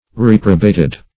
Reprobate \Rep"ro*bate\ (-b?t), v. t. [imp. & p. p. Reprobated